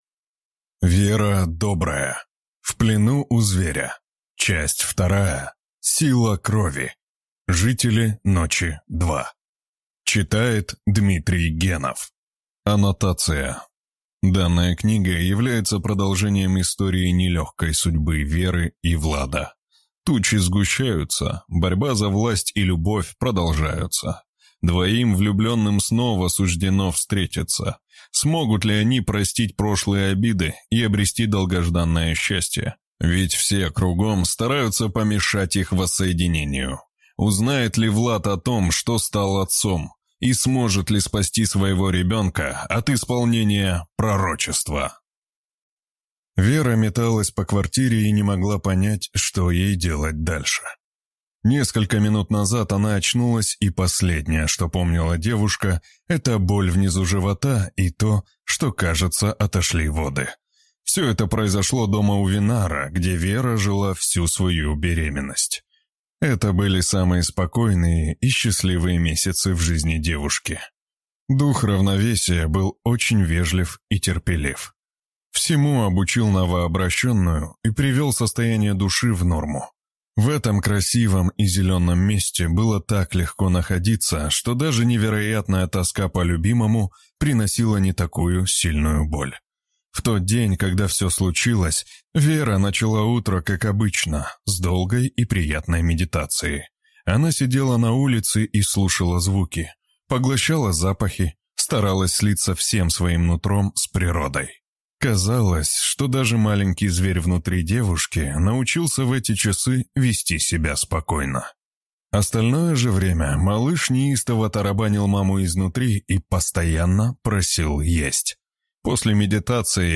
Аудиокнига В плену у Зверя. Сила крови. Книга 2 | Библиотека аудиокниг